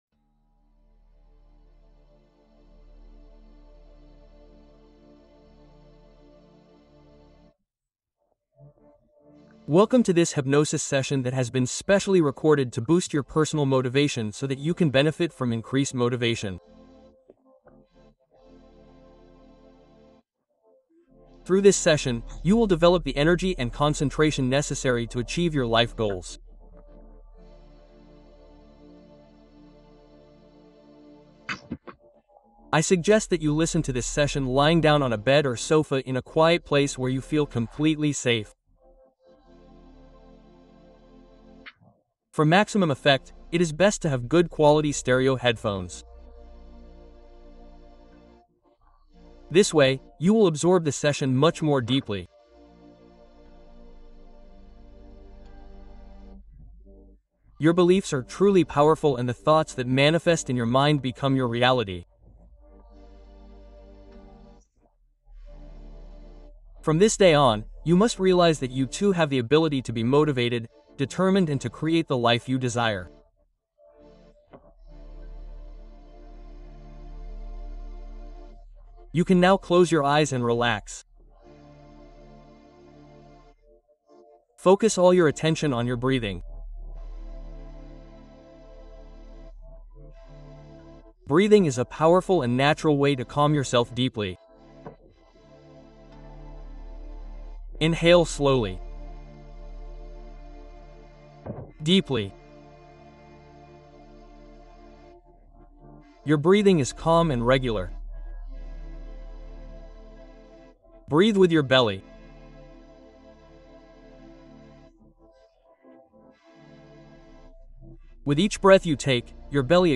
Hypnose pour amplifier ta confiance en quelques minutes